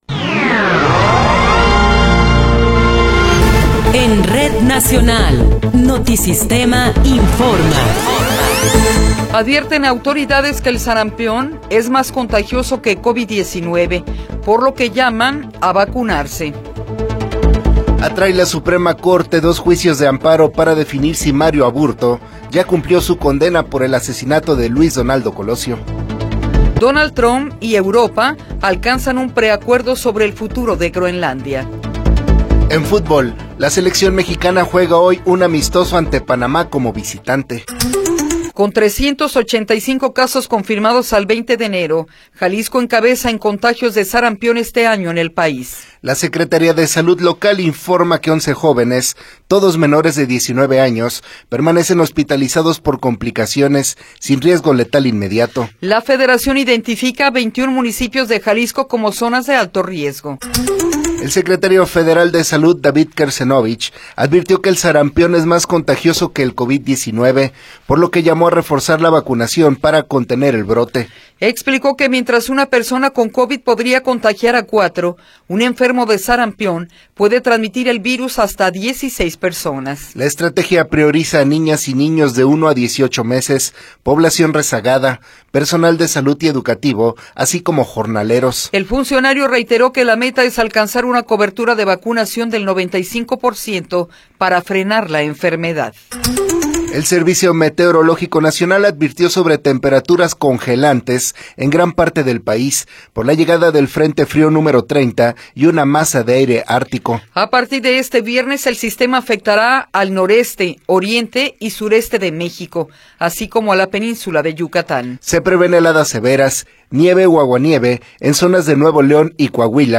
Noticiero 8 hrs. – 22 de Enero de 2026
Resumen informativo Notisistema, la mejor y más completa información cada hora en la hora.